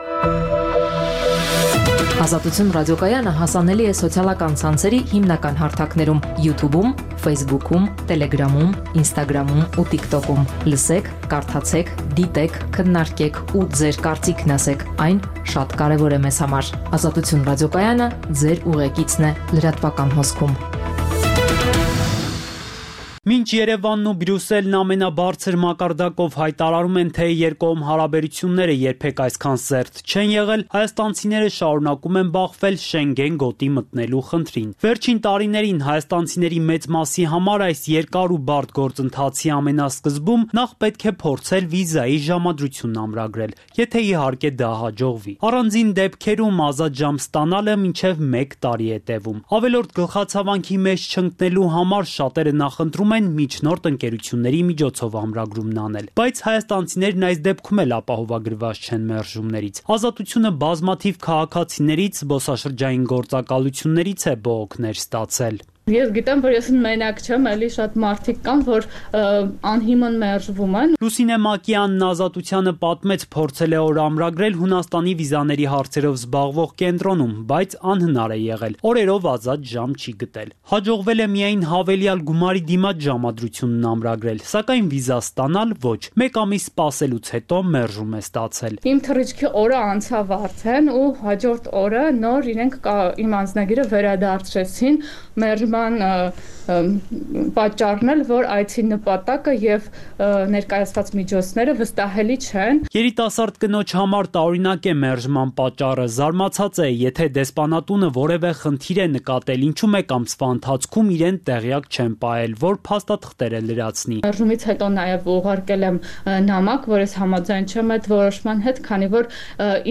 «Ազատություն» ռադիոկայանի առավոտյան ծրագիր